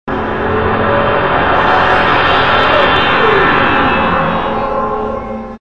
cloak_rh_battleship.wav